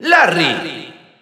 Announcer pronouncing Larry in French.
Larry_Spanish_Announcer_SSBU.wav